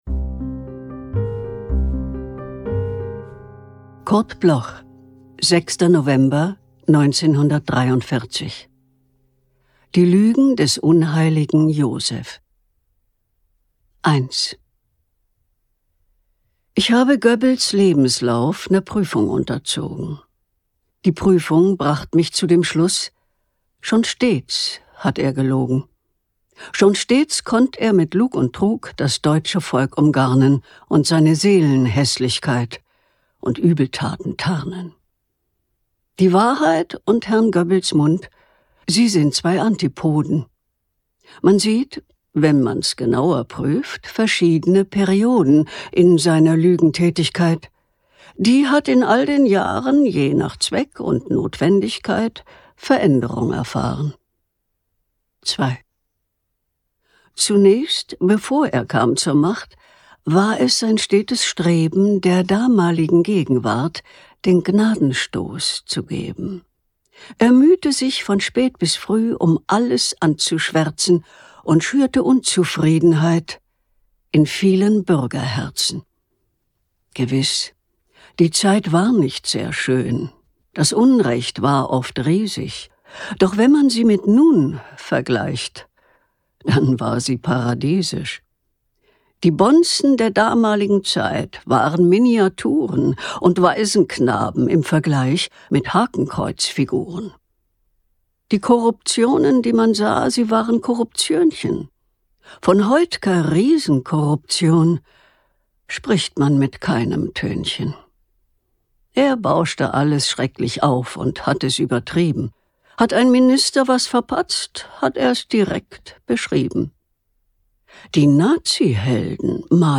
aufgenommen im Studio von Alias Film und Sprachtransfer, Berlin / bearbeitet von Kristen & Schmidt, Wiesbaden